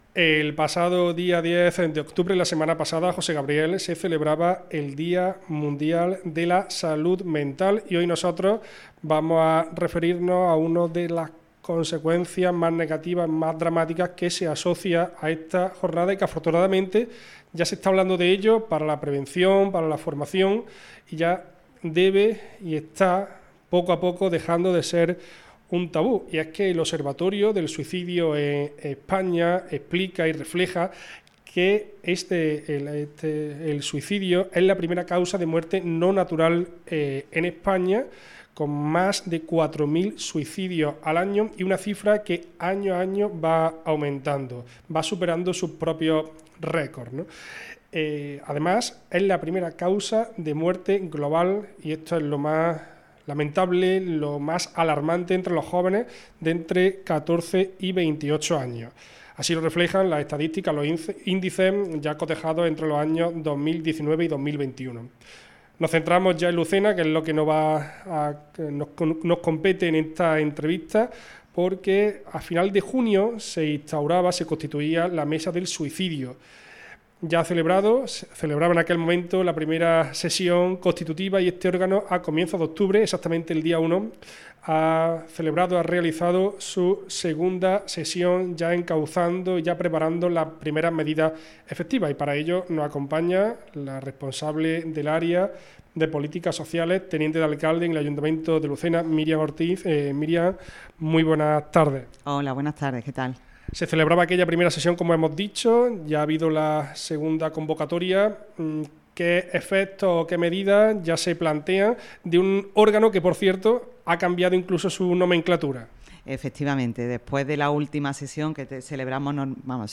10-14-24 Entrevista Lucena-Segunda sesión Mesa Suicidio - Andalucía Centro